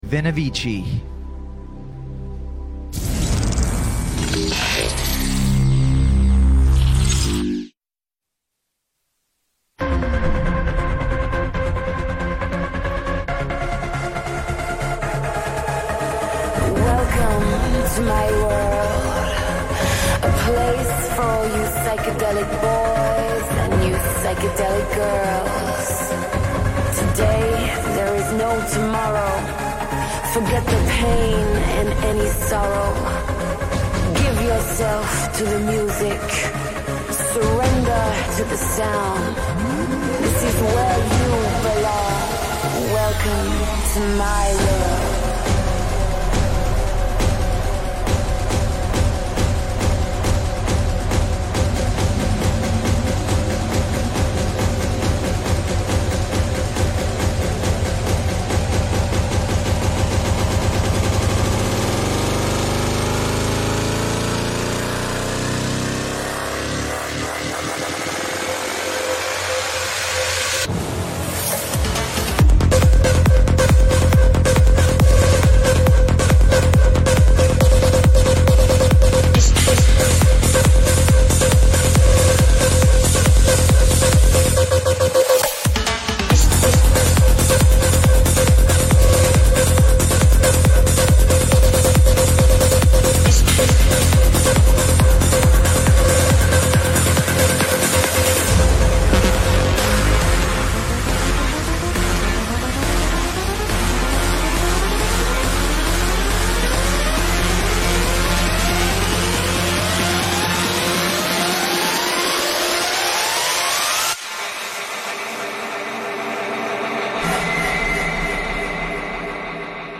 Liveset
Genre: Trance